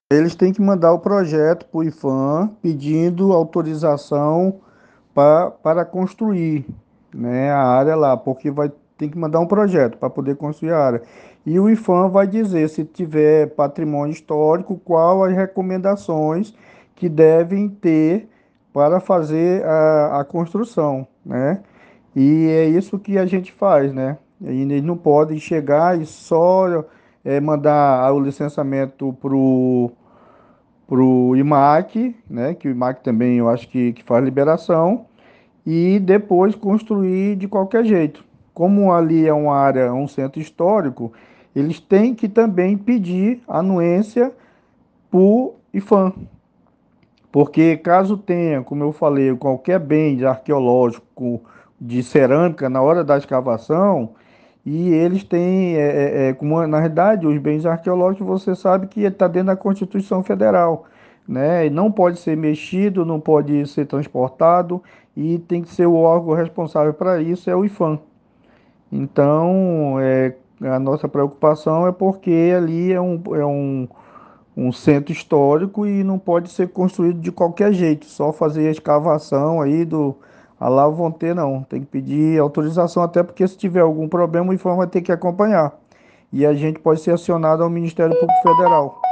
Ele acrescenta que o Ministério Público Federal pode ser acionado ante o descumprimento das regras.  Ouça a entrevista abaixo.